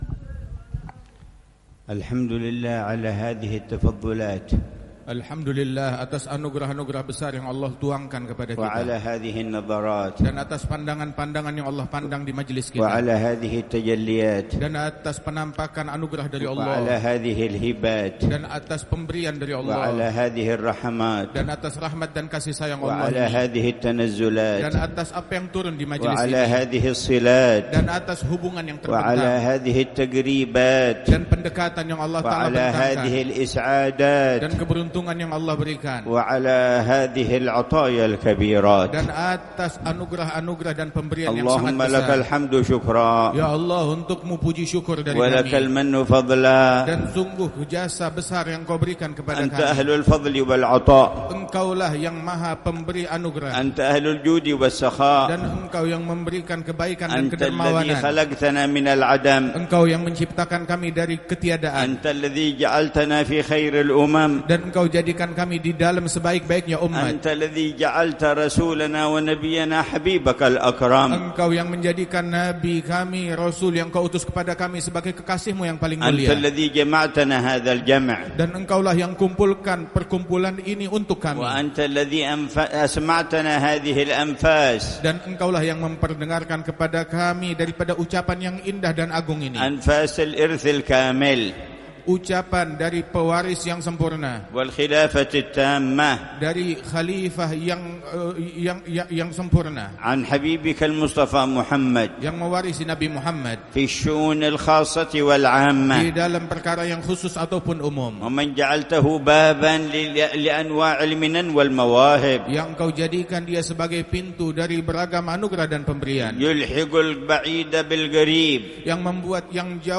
محاضرة العلامة الحبيب عمر بن محمد بن حفيظ، في المولد السنوي ضمن ذكرى حولية الإمام علي بن محمد الحبشي، في مسجد الرياض، بمدينة صولو، جاوة الوسطى، إندونيسيا، صباح الإثنين 21 ربيع الأول 1447هـ بعنوان: